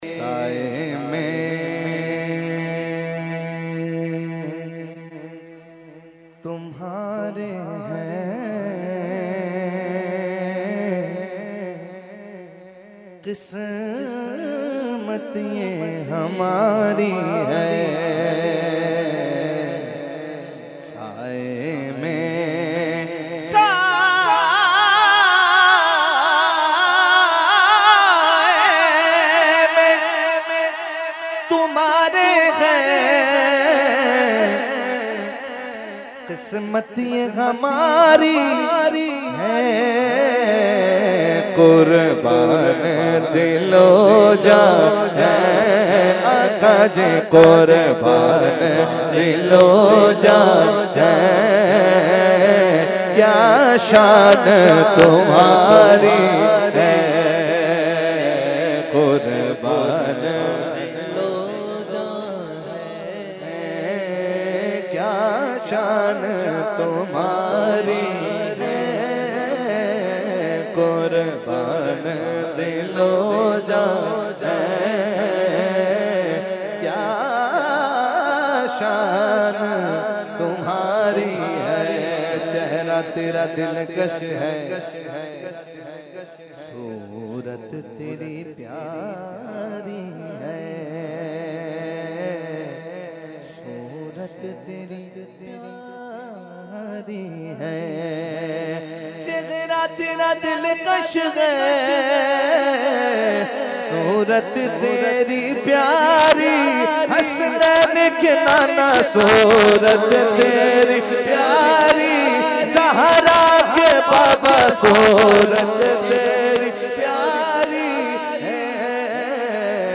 Category : Naat | Language : UrduEvent : Urs Ashraful Mashaikh 2019